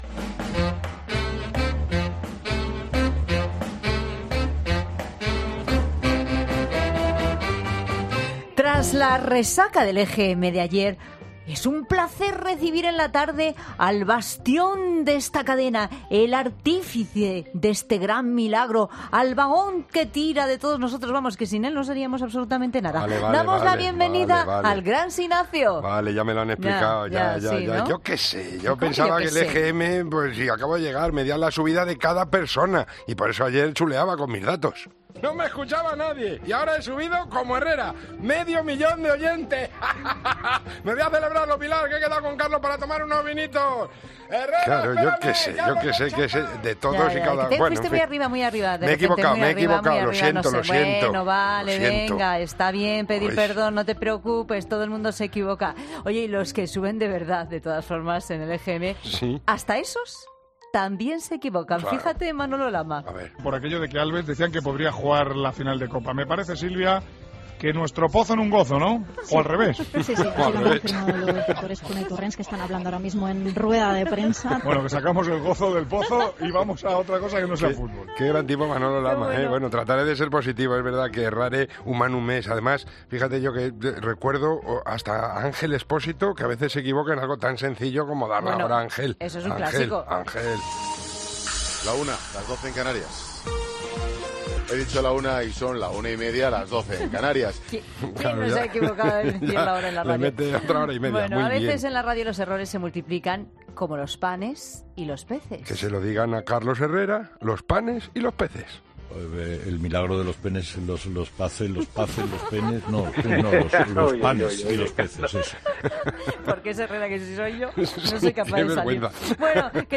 En primer lugar, algunos cometidos por los grandes comunicadores de Cope, que a veces se les lengua la traba, o más bien se les traba la lengua. Escucharemos algunos lapsus de tres grandes: Carlos Herrera, Manolo Lama y Ángel Expósito .